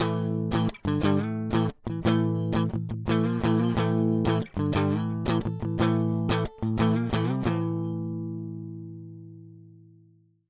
Marshall 2555x style Amp:
DI Signal